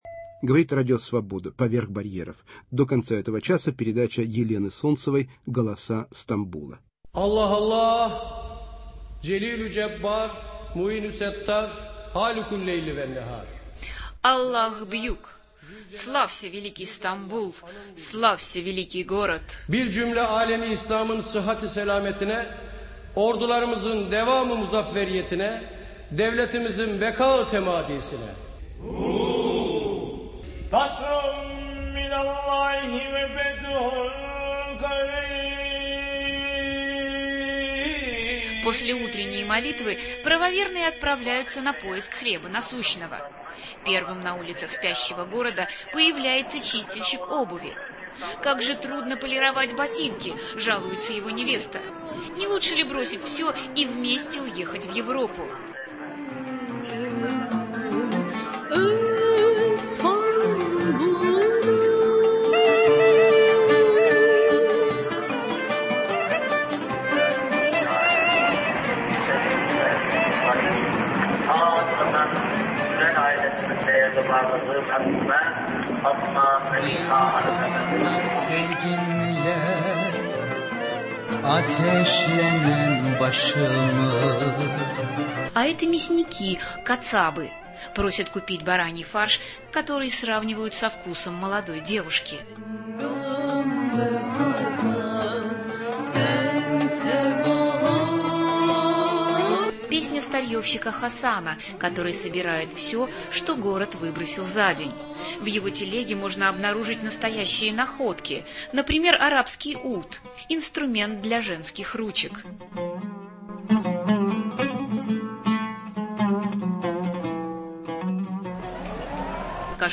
"Голоса Стамбула". Акустичекский портрет города, в котором слышна турецкая, русская. армянская и греческая речь